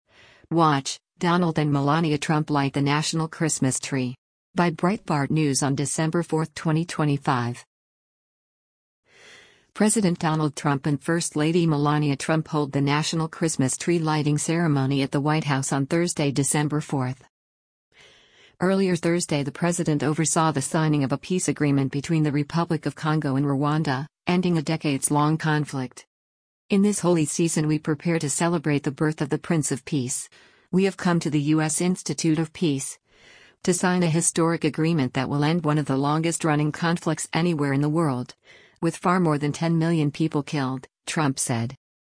President Donald Trump and First Lady Melania Trump hold the national Christmas tree lighting ceremony at the White House on Thursday, December 4.